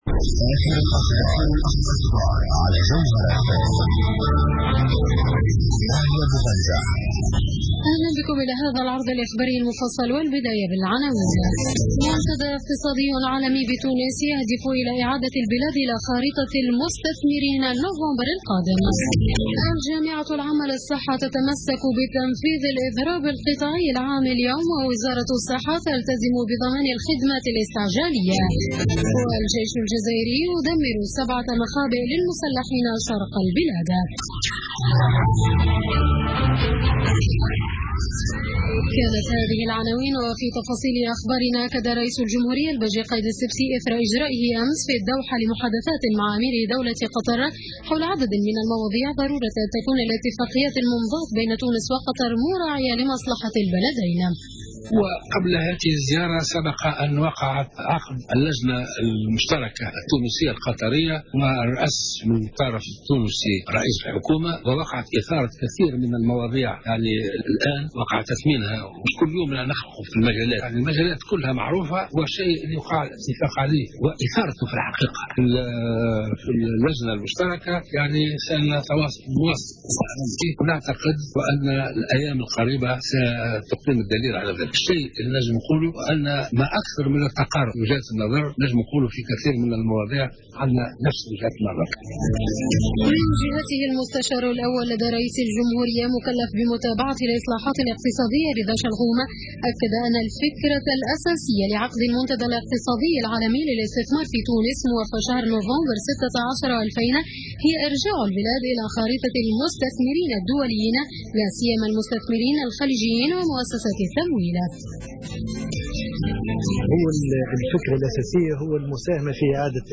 نشرة أخبار منتصف الليل ليوم الخميس 19 ماي 2016